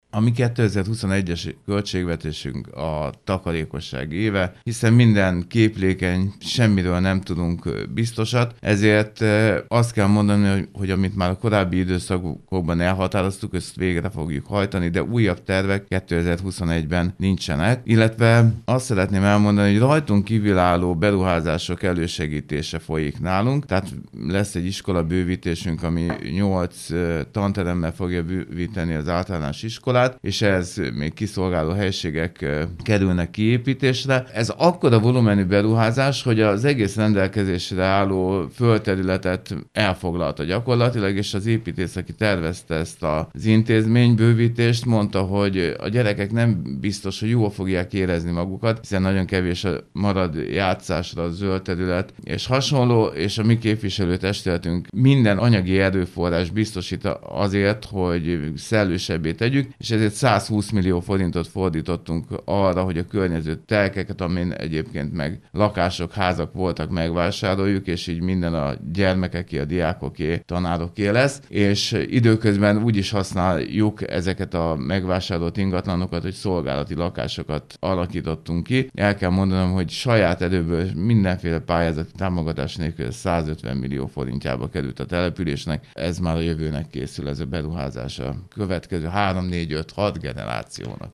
Dr. Gál Imre polgármester arról beszélt rádiónkban, országosan hatalmas probléma a szemetelés.